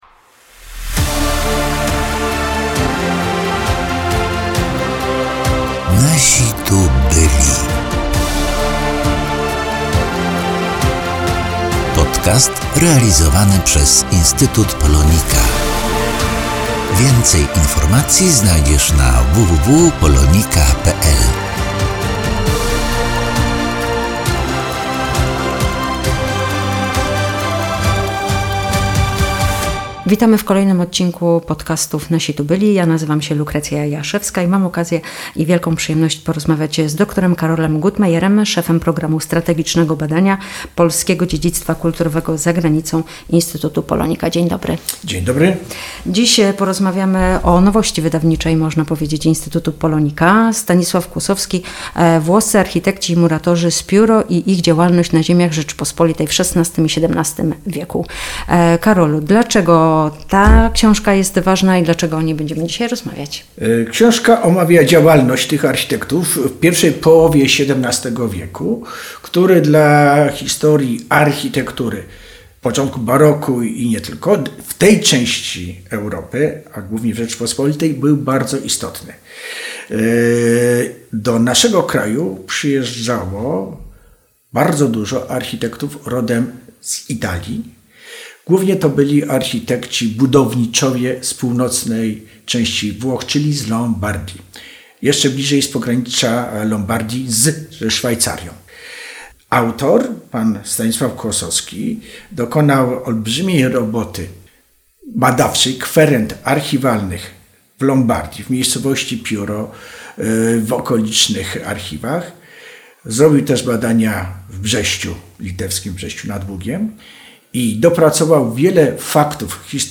Rozmowa o włoskich architektach z Piuro mp3 (30.47 MB)